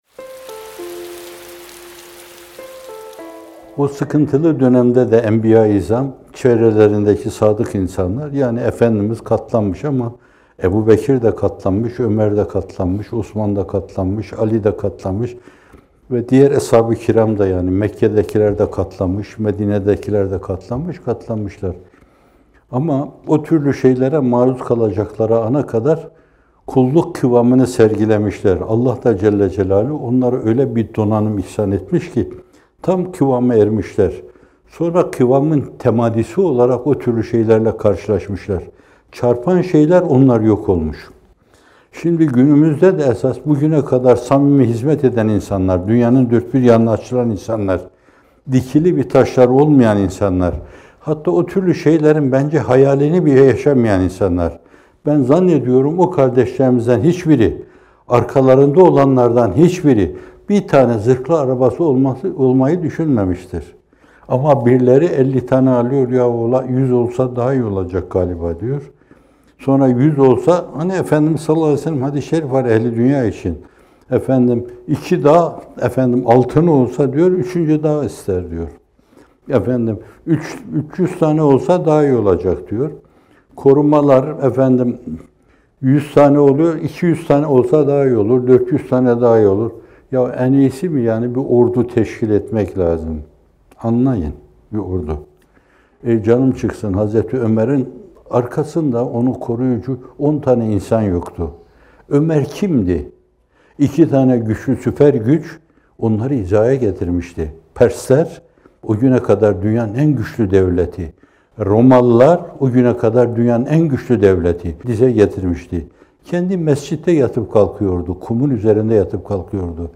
İkindi Yağmurları – Hizmet İçin Gittim Dönemem - Fethullah Gülen Hocaefendi'nin Sohbetleri
Not: Bu video, 6 Ekim 2019 tarihinde yayımlanan “Yol, Çile ve Akıbet” isimli Bamteli sohbetinden hazırlanmıştır.